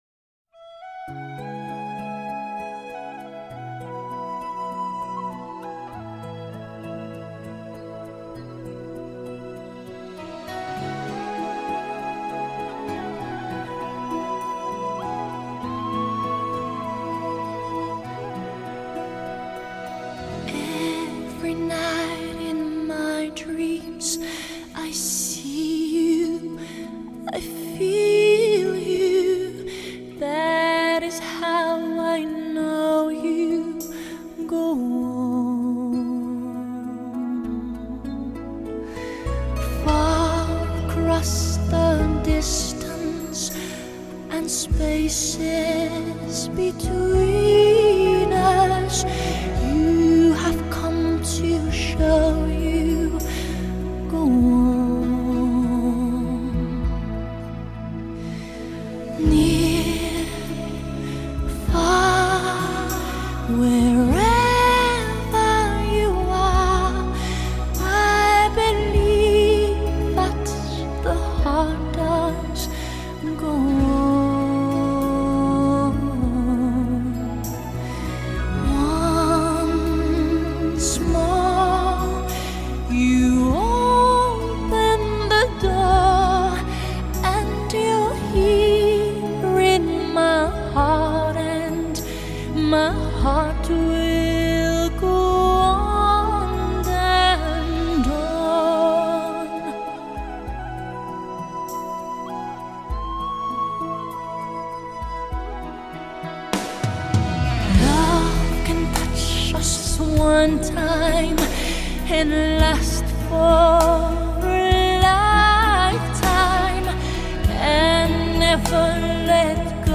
دانلود ریمیکس همین موزیک